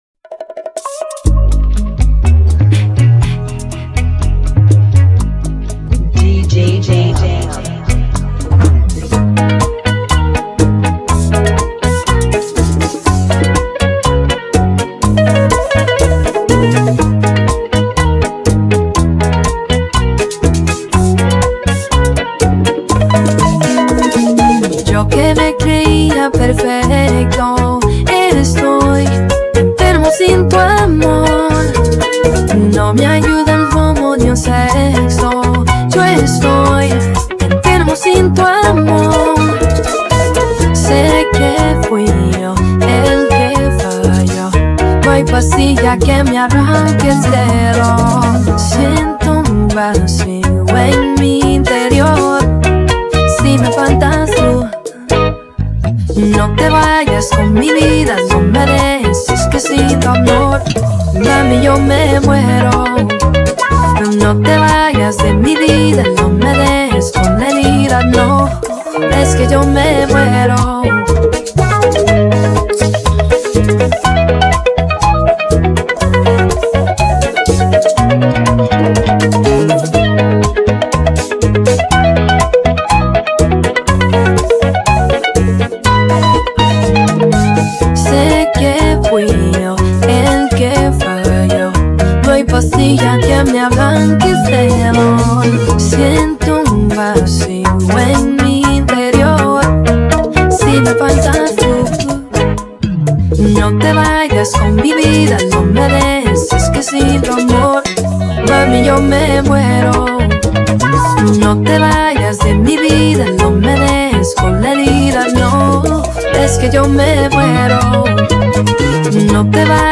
Bachata Remix